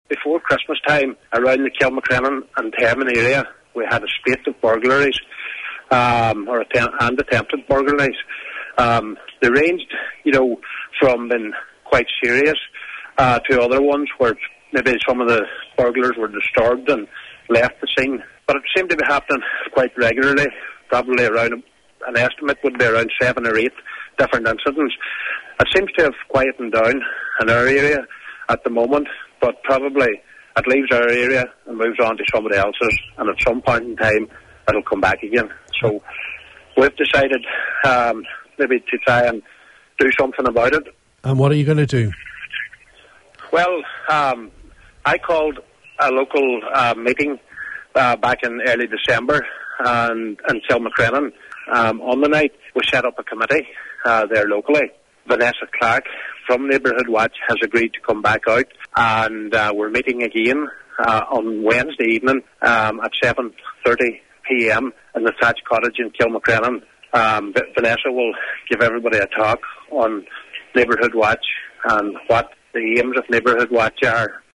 Local Councillor Michael McBride is helping set up the scheme – he says a meeting will takes place this Wednesday evening in the Thatched Cottage in Kilmacrenan at 7.30pm to progress the plans: